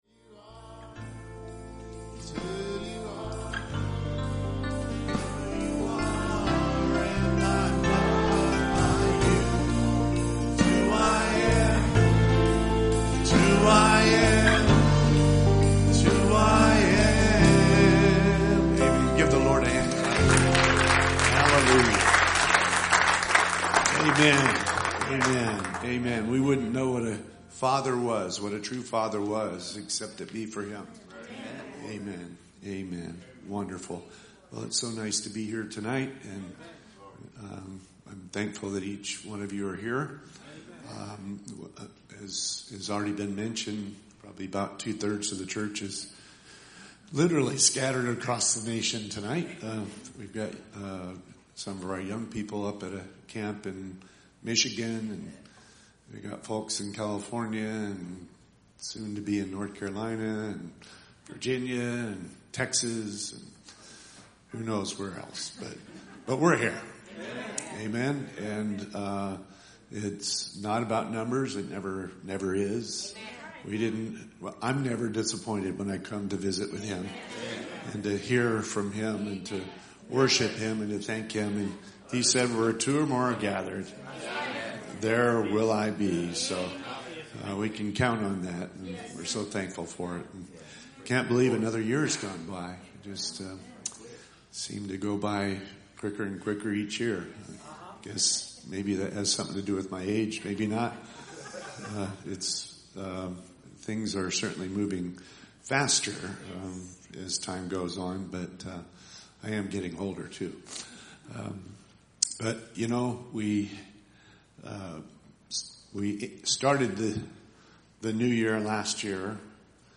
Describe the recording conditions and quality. Watchnight Services